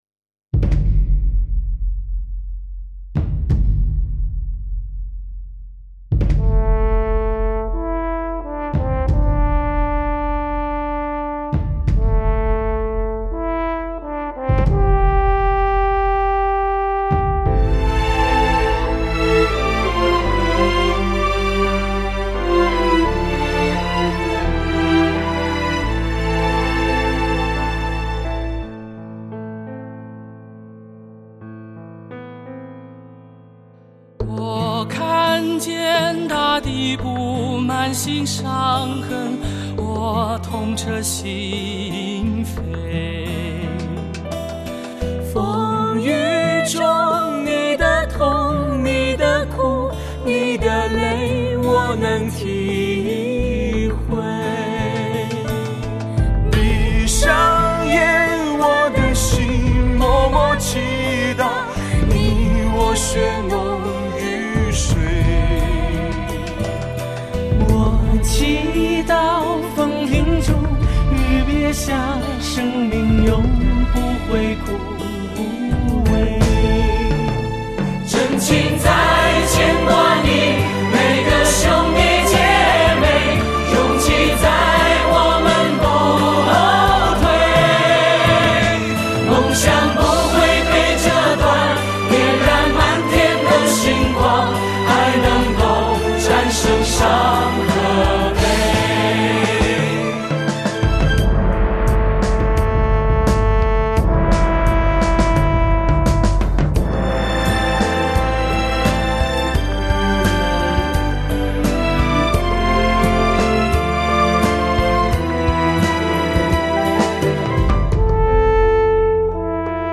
合唱
法国号